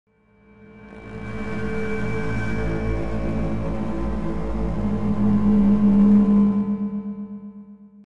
39_scarySound.mp3